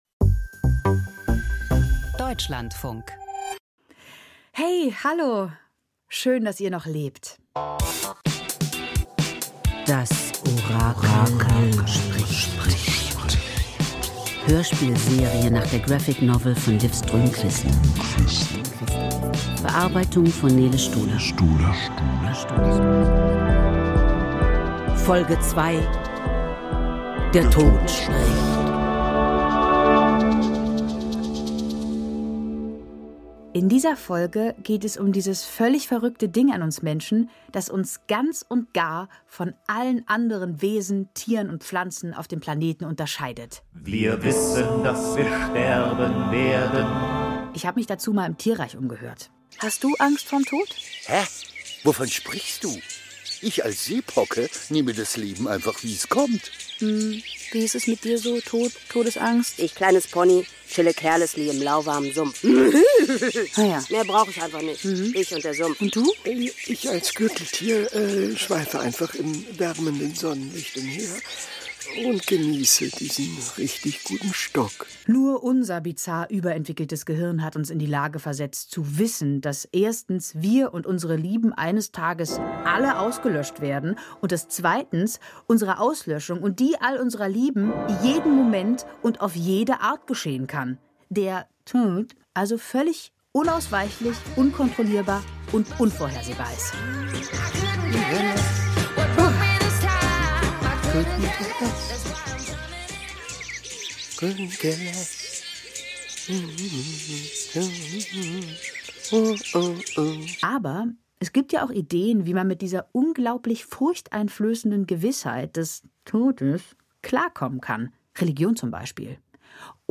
Das Orakel spricht – Hörspielserie nach Liv Strömquist